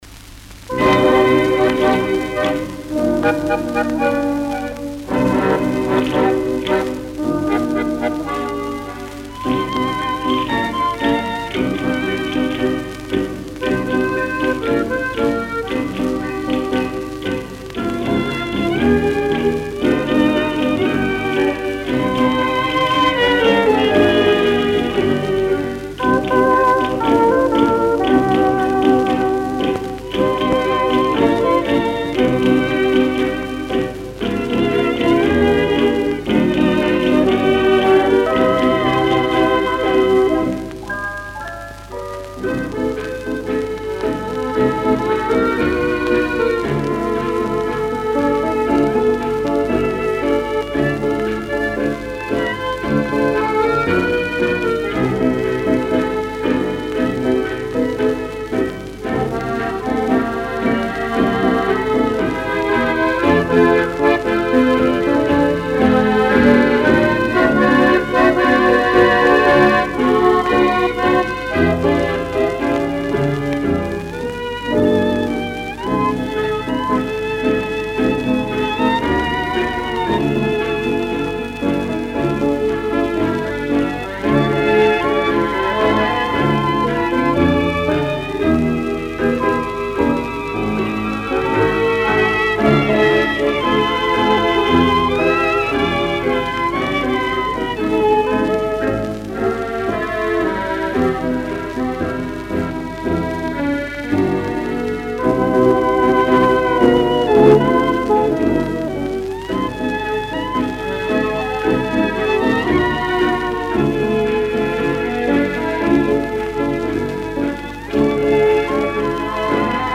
Каталожная категория: Танцевальный оркестр |
Жанр: Танго
Место записи: Берлин |